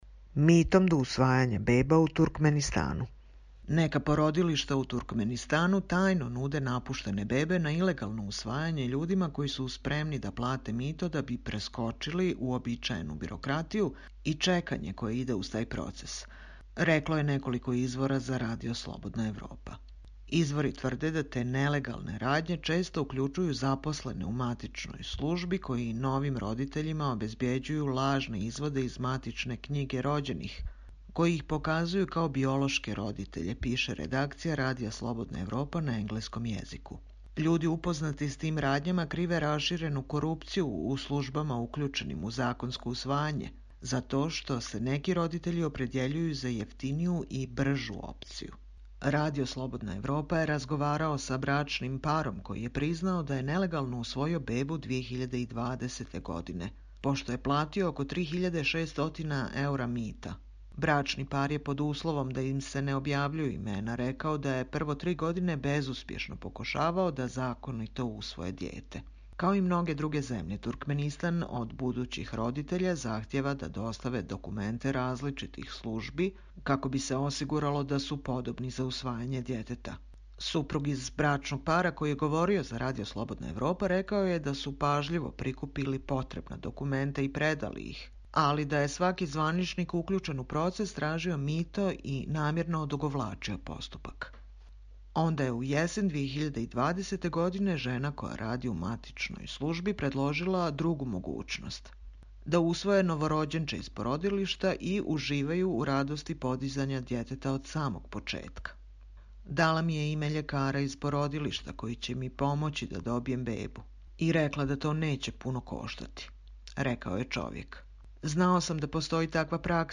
Čitamo vam: Mitom do usvajanja beba u Turkmenistanu